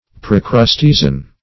Procrustesian \Pro`crus*te"si*an\, a.